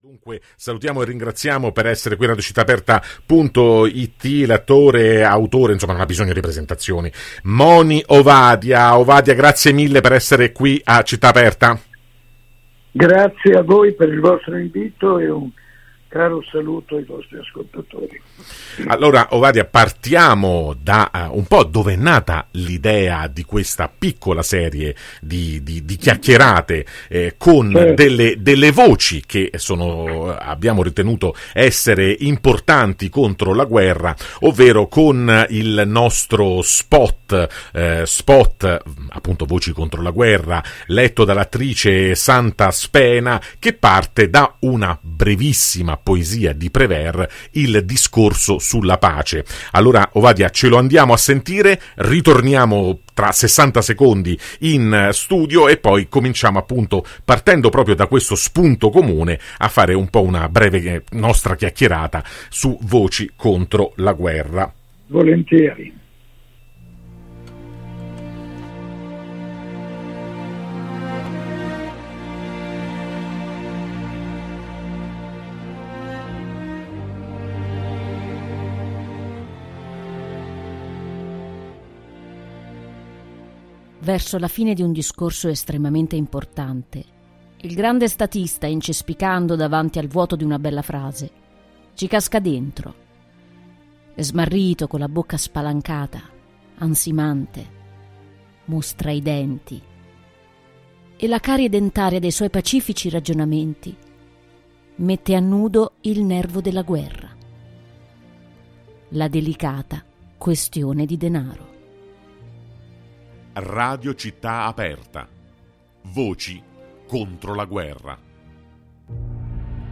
In questa puntata l’attore e autore Moni Ovadia ci spiega le sue ragioni per essere una “Voce contro la guerra”, analizzando i difficili conflitti alle porte d’Europa ed esprimendo la netta condanna per ciò che sta avvenendo nel conflitto israelo-palestinese.